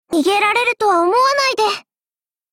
Cv-10808_warcry.mp3